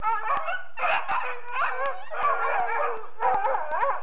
دانلود صدای حیوانات جنگلی 47 از ساعد نیوز با لینک مستقیم و کیفیت بالا
جلوه های صوتی